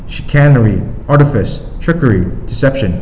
[doo- plis -i-tee, dyoo-]